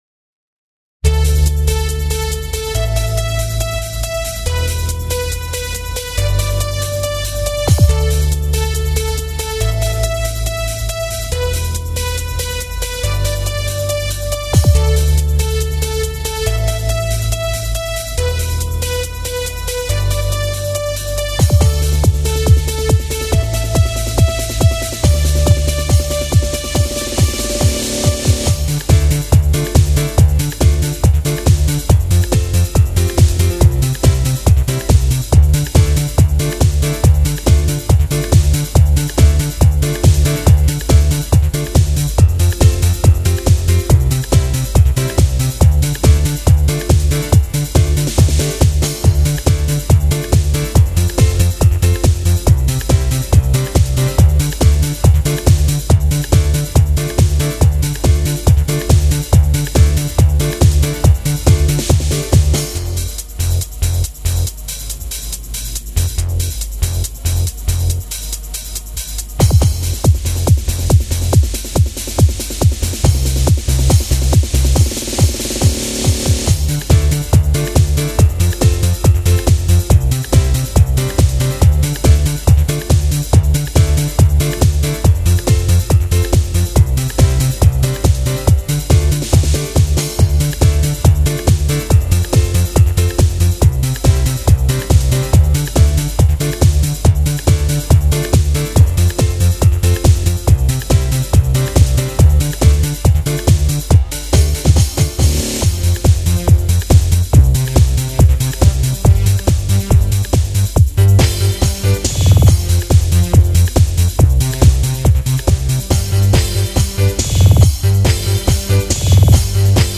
Dieser rhythmische Dancemix